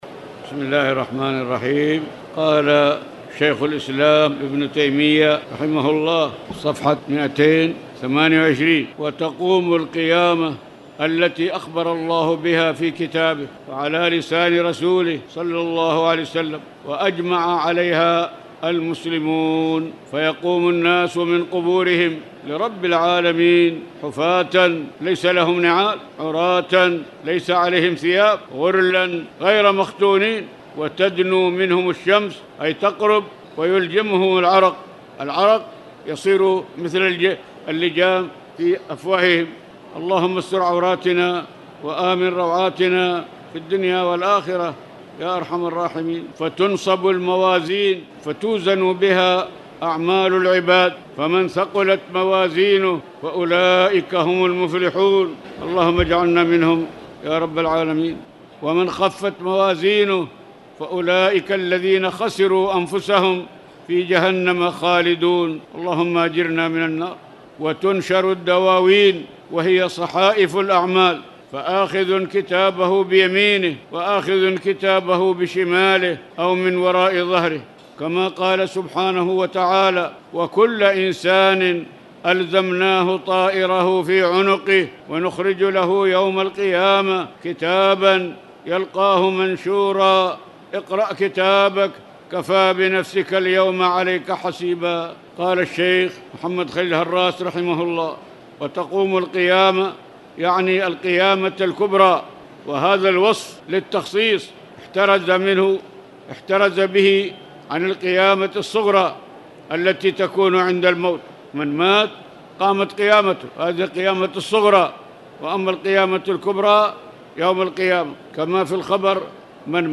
تاريخ النشر ٩ رمضان ١٤٣٨ هـ المكان: المسجد الحرام الشيخ